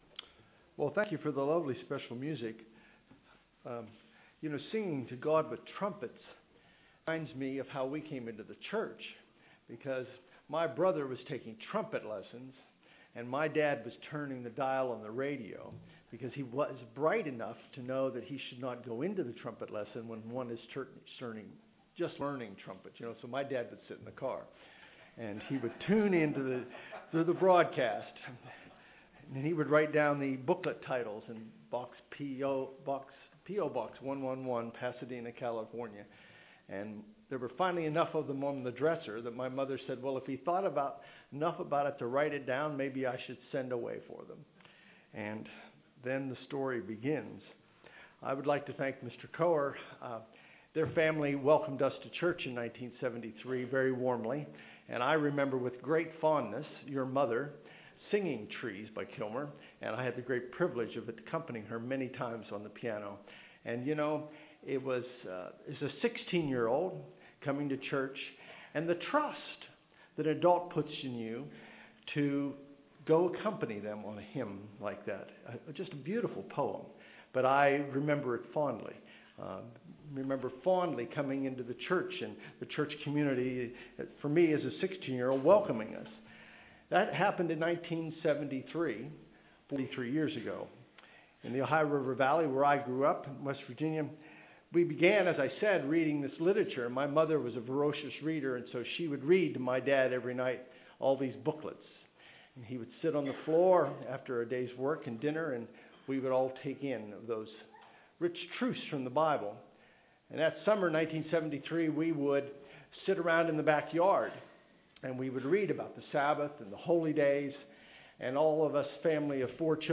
This sermon was given at the Galveston, Texas 2016 Feast site.